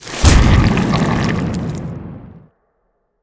slime.wav